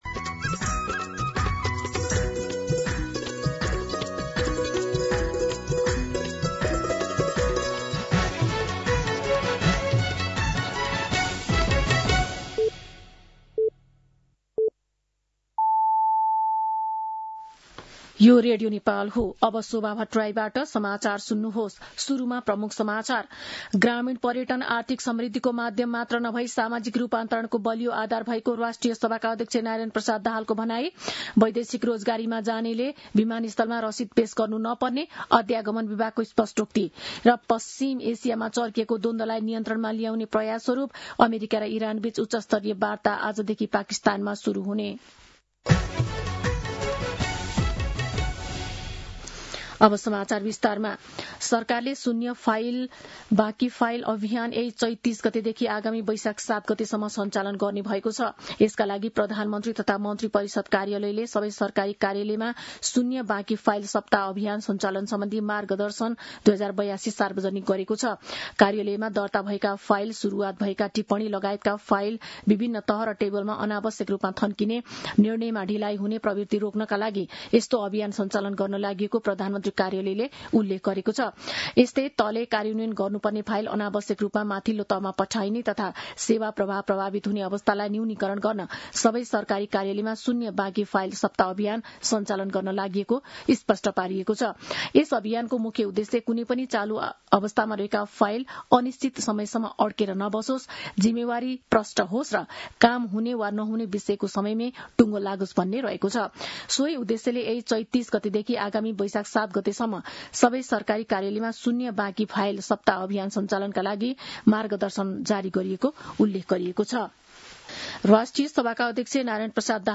दिउँसो ३ बजेको नेपाली समाचार : २८ चैत , २०८२